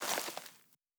added stepping sounds
Undergrowth_Mono_05.wav